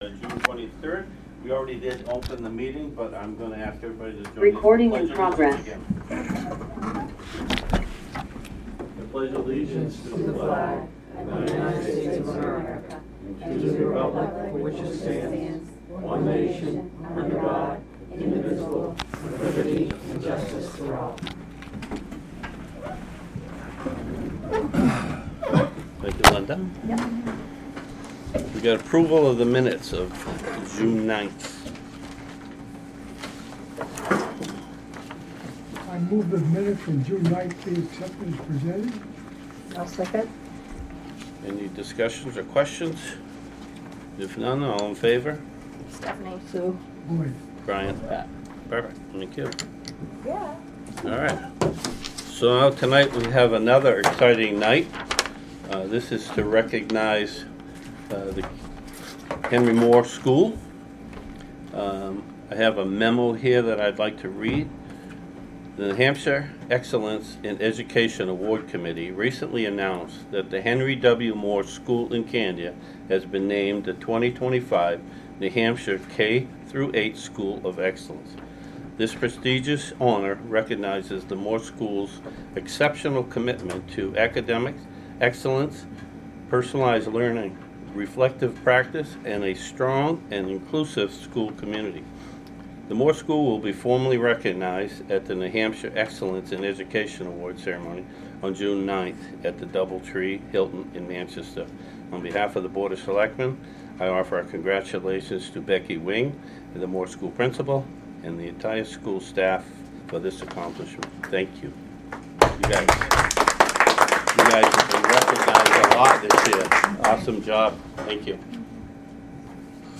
Audio recordings of committee and board meetings.